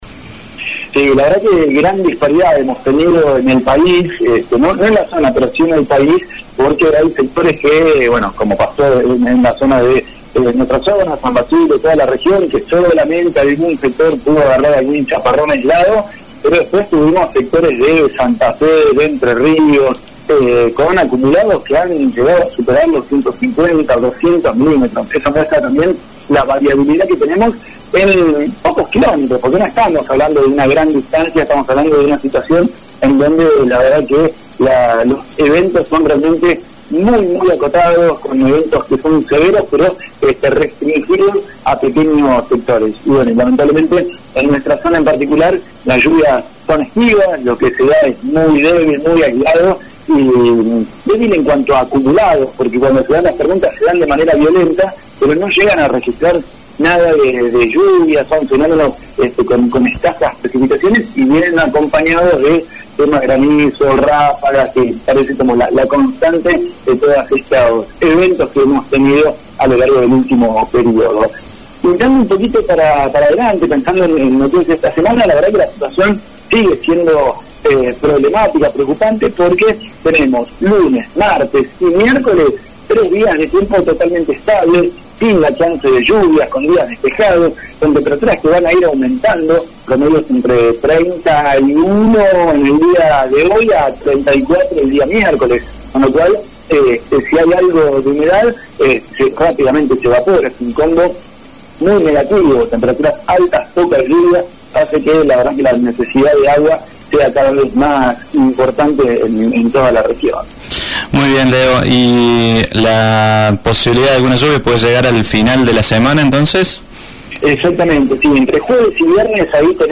el pronóstico del tiempo para la jornada de hoy.